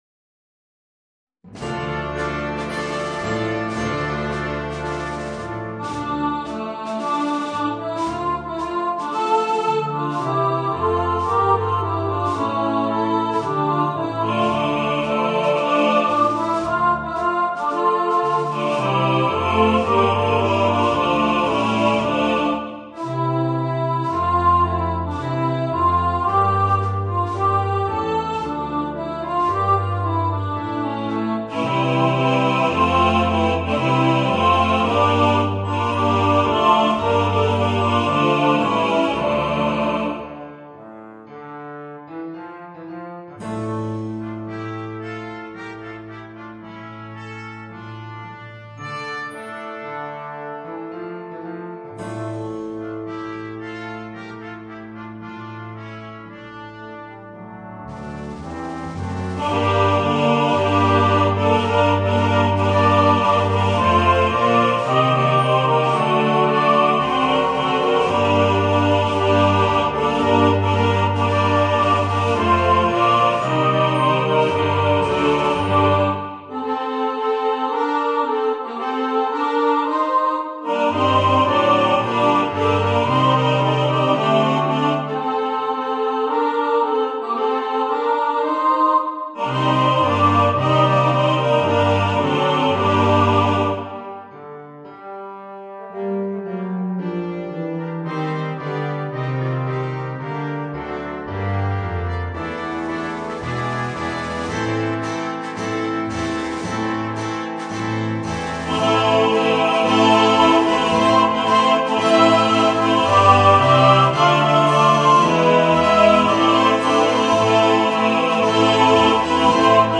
Voicing: Chorus